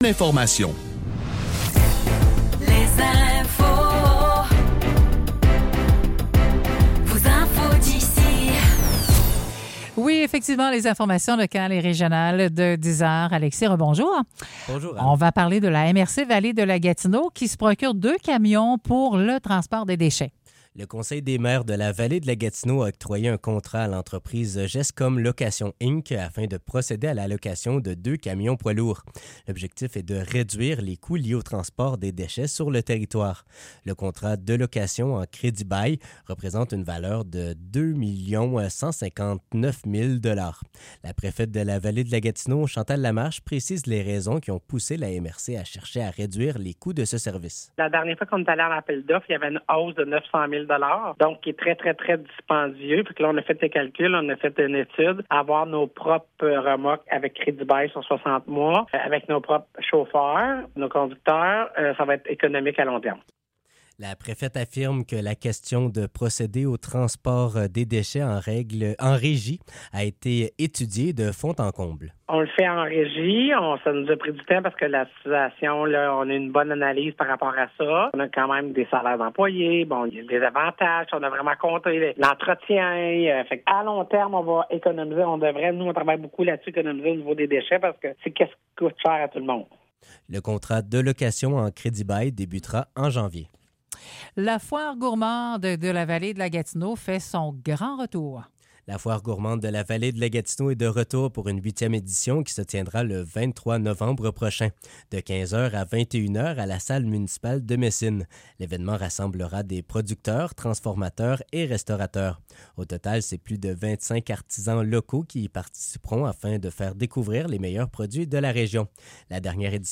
Nouvelles locales - 20 novembre 2024 - 10 h